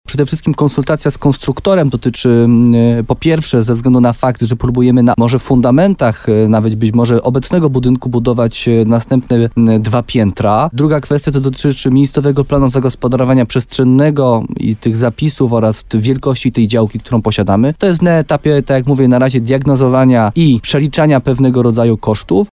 – Szczegóły, także pod kątem pojemności widowni, są jeszcze ustalane – mówi wójt Jarosław Baziak.